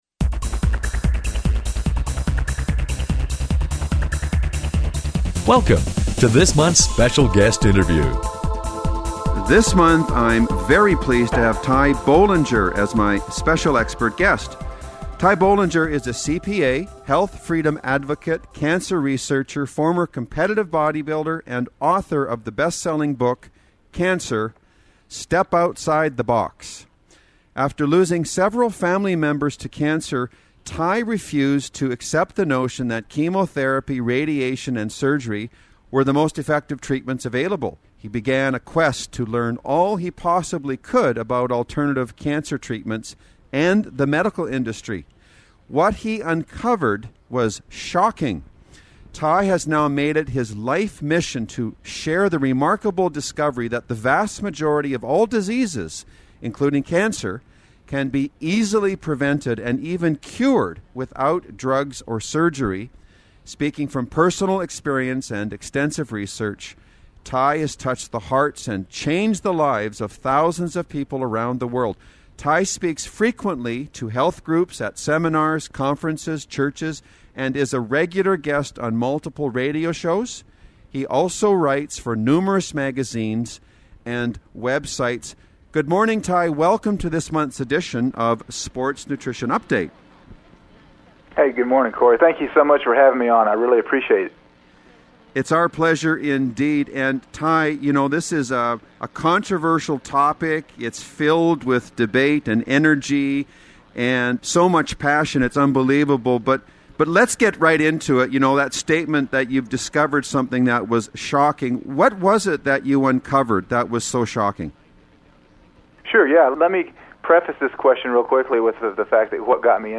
Special Guest Interview Volume 10 Number 10 V10N10c